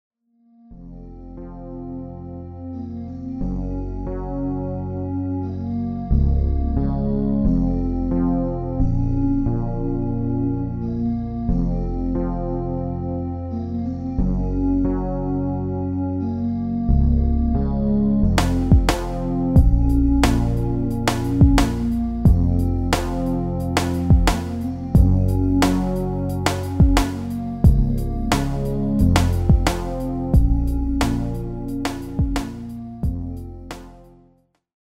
Demo/Koop midifile
Genre: Dance / Techno / HipHop / Jump
- Géén vocal harmony tracks
Demo's zijn eigen opnames van onze digitale arrangementen.